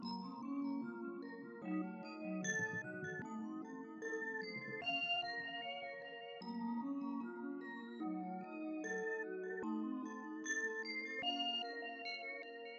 练习1.3 " 叮叮当当的声音
标签： 贝尔 奉贡
声道立体声